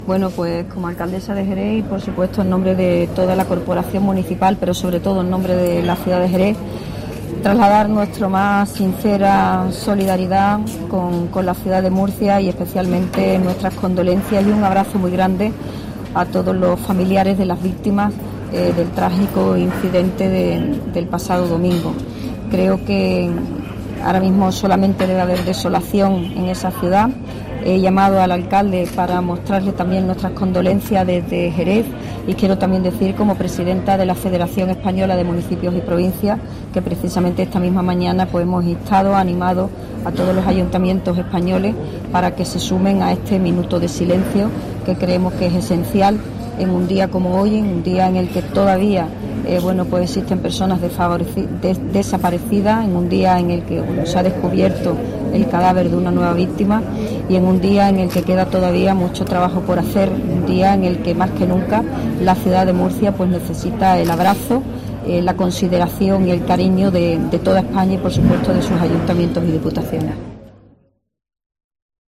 Minuto de silencio